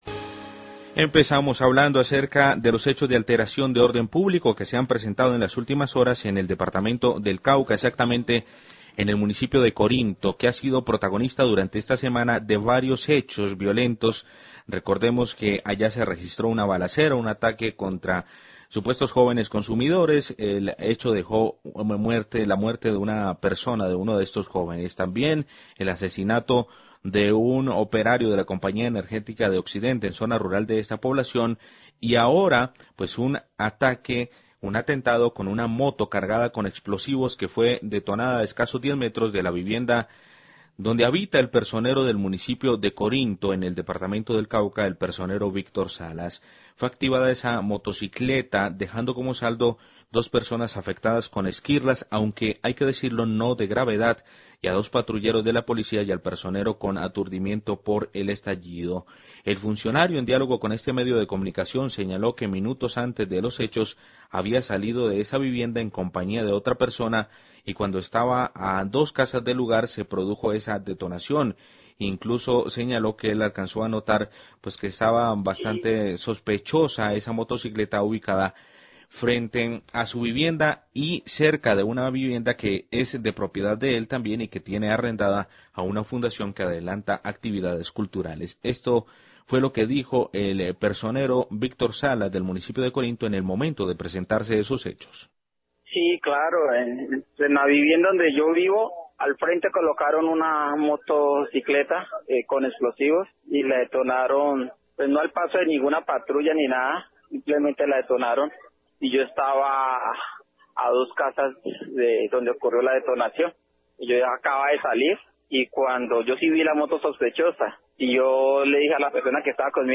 Radio
Hoy habrá un Consejo de Seguridad para analizar la situación. Declaraciones de Víctor Salas, Personero de Corinto y Eduard Garcia, Alcalde de Corinto.